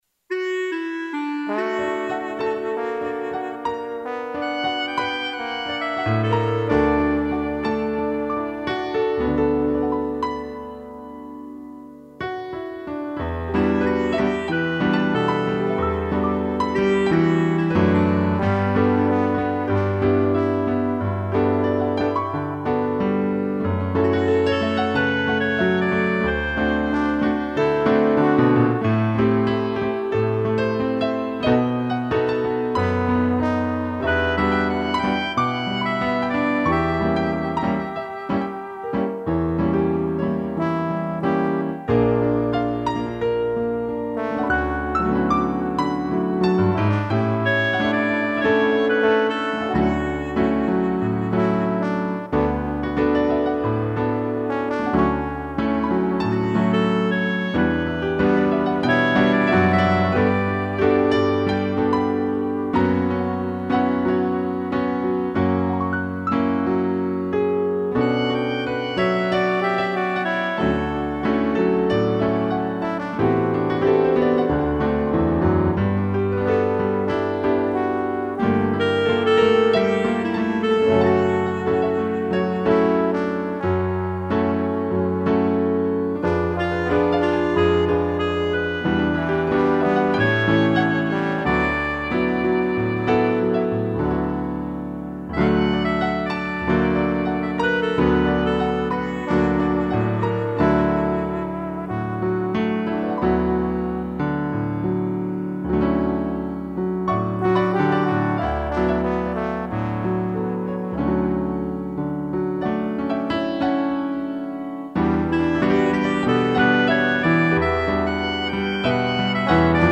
2 pianos, clarineta e trombone
(instrumental)